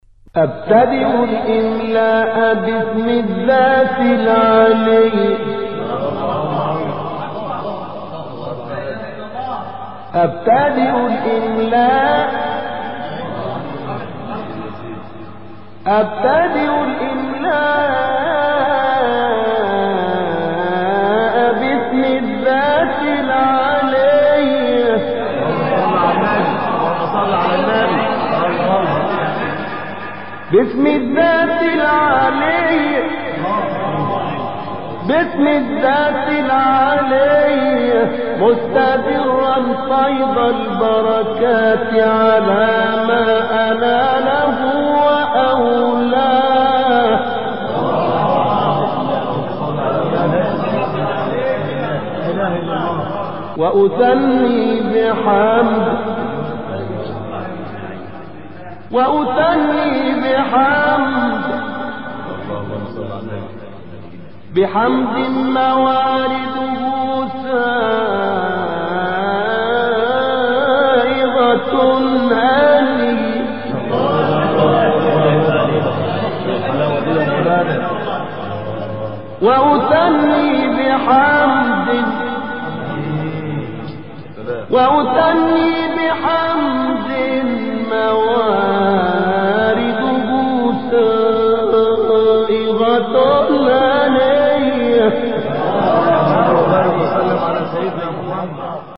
گروه فعالیتهای قرآنی: فرازهای صوتی دلنشین با صوت قاریان ممتاز و بین‌المللی قرآن که طی این روزها در شبکه‌های اجتماعی منتشر شده است، ارائه می‌شود.